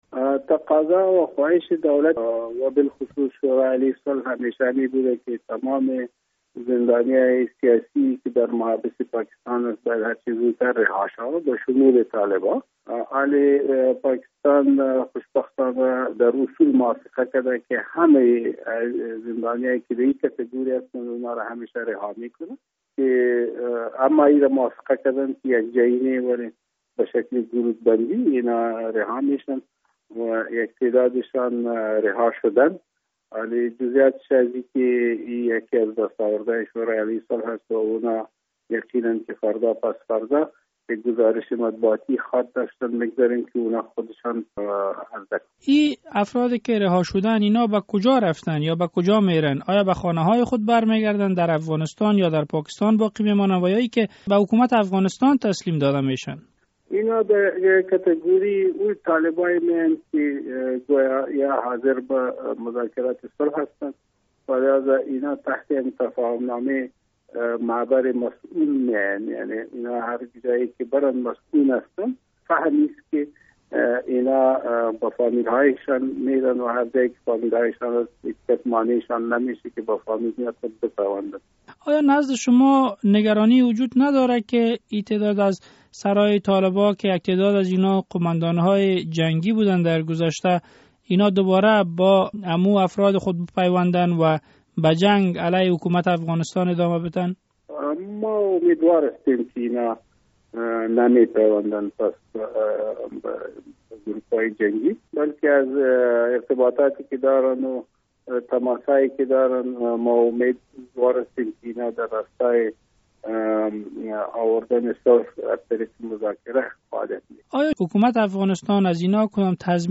سفیر افغانستان در اسلام آباد می گوید: پاکستان توافق کرده تا تمامی زندانیان طالبان در آن کشور را رها کند. محمد عمر داودزی در صحبت با رادیو آزادی گفت: مقامات پاکستانی در اصول توافق کرده و در نخستین مرحله، شماری از